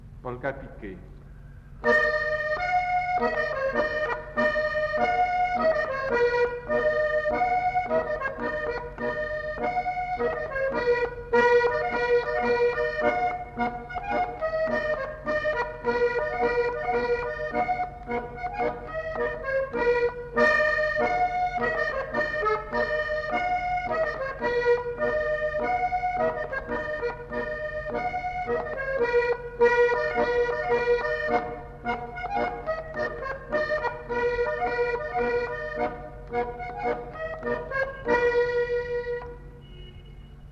enquêtes sonores
Polka piquée